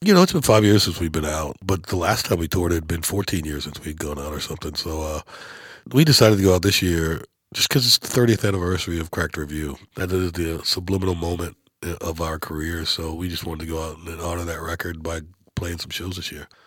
Darius Rucker talks about the decision for Hootie & The Blowfish to tour this year.